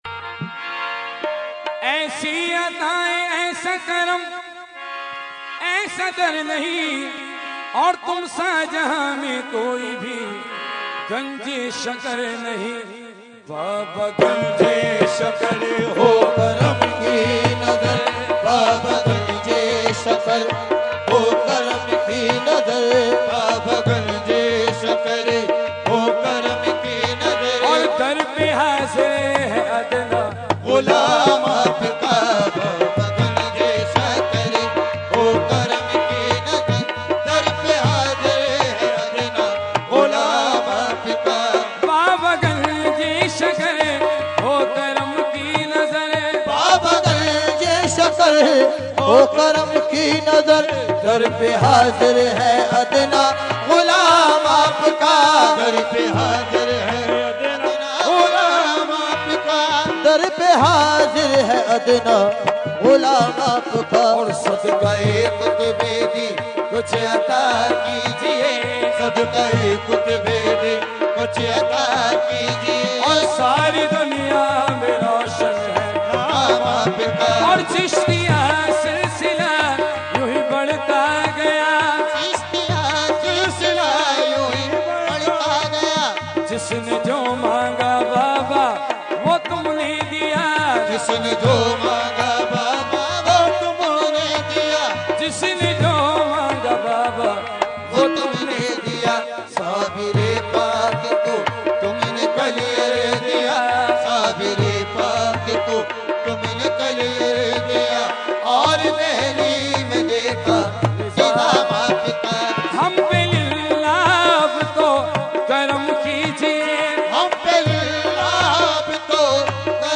Category : Qawali | Language : UrduEvent : Urs e Qutb e Rabbani 2013